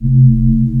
SPACEMOTOR.wav